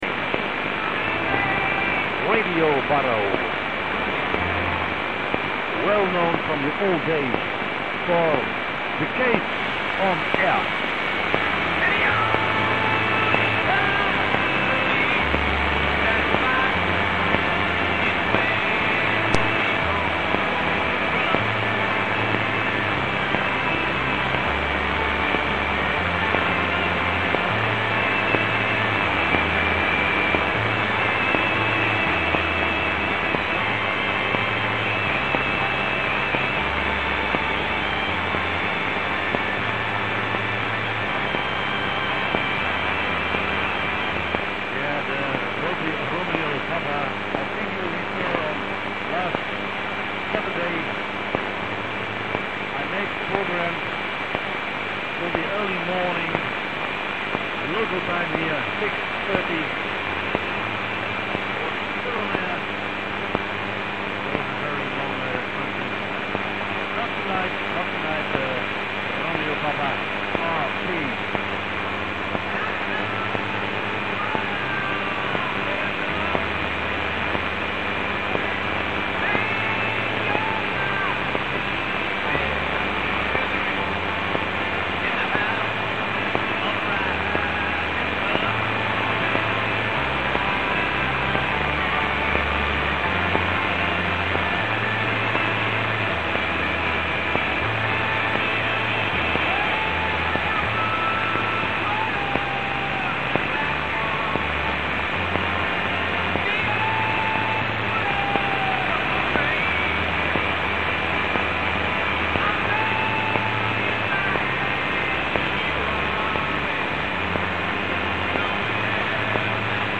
Egy kis hiánypótlás...egy hete nem raktam fel a holland adóról készült felvételt :D
1625 kHz